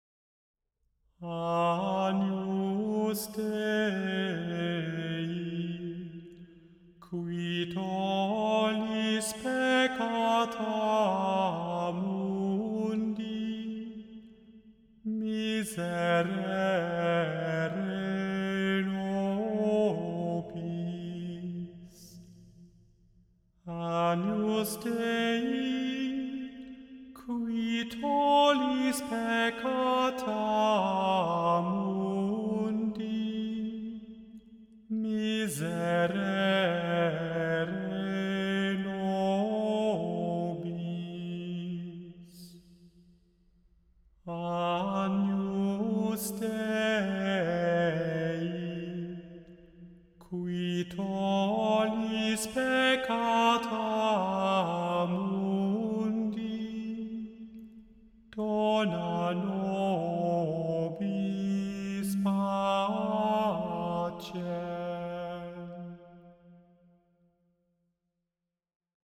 The Chant Project – Chant for Today (August 2) – Agnus Dei (Cunctipotens genitor Deus)
Agnus-Dei-Cunctipotens-genitor-Deus.mp3